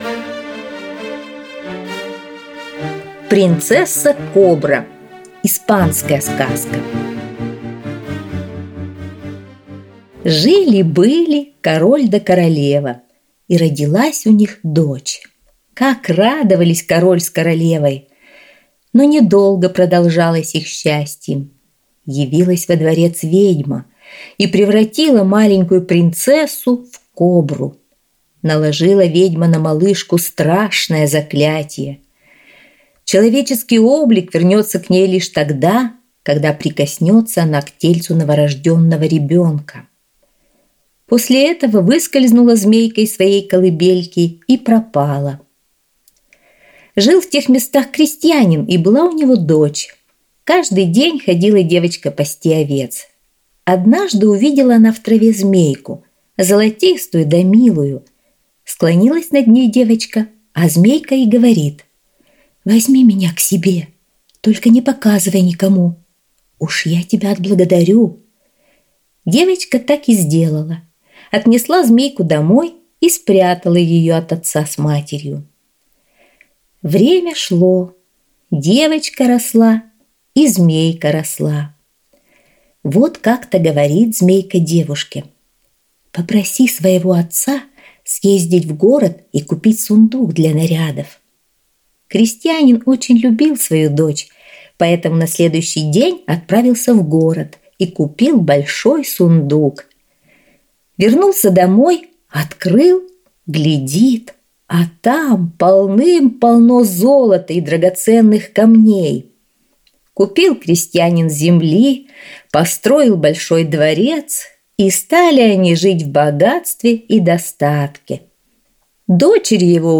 Аудиосказка «Принцесса-кобра»